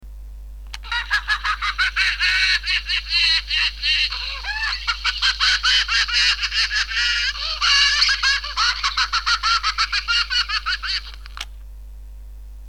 Nauru.mp3